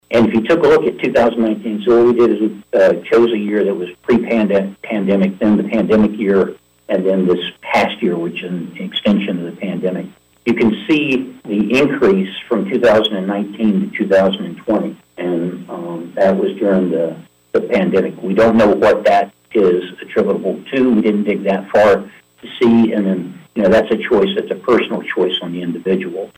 Branch County Administrator Bud Norman indicated during the Board of Commissioners work session on Thursday that their situation mirrors what’s going on around the country right now when it comes to filling open positions.
During a power point presentation, he went over statistics between 2019 and 2021.